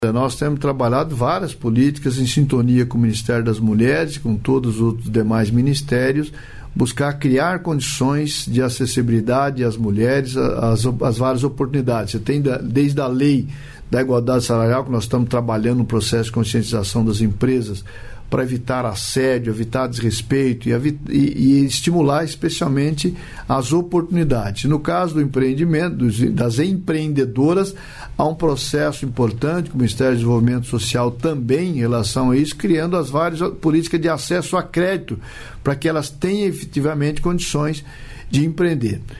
Trecho da participação do ministro do Empreendedorismo, da Microempresa e da Empresa de Pequeno Porte, Márcio França, no programa "Bom Dia, Ministro" desta quinta-feira (30), nos estúdios da EBC, em Brasília. 1'57"